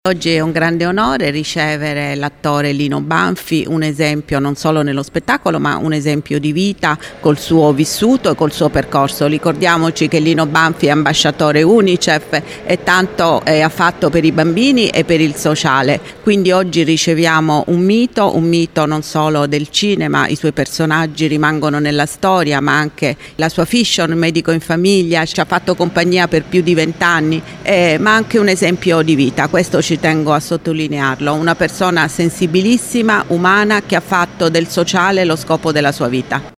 Un incontro carico di emozione e simpatia quello che si è tenuto oggi al foyer del Teatro D’Annunzio di Latina, dove Lino Banfi ha presentato in anteprima lo spettacolo che andrà in scena il 27 novembre, inserito nel cartellone della stagione teatrale 2025-2026 del teatro comunale.